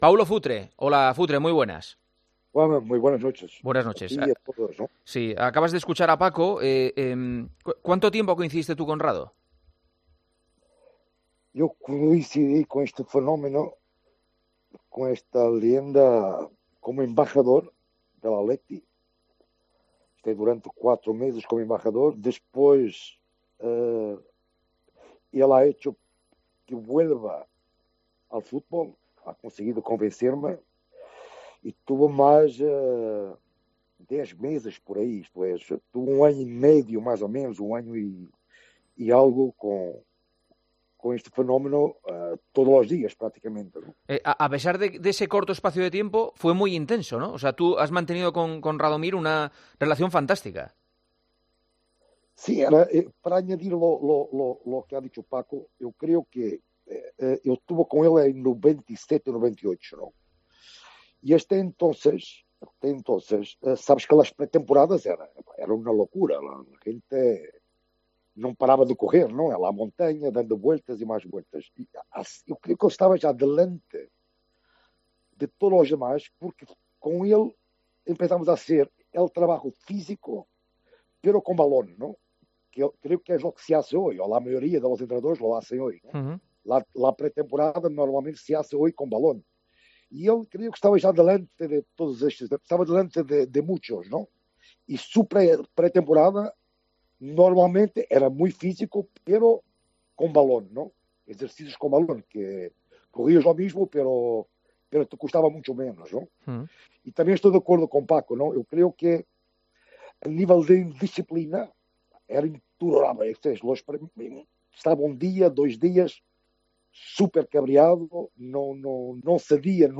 AUDIO - ENTREVISTA A PAULO FUTRE, EN EL PARTIDAZO DE COPE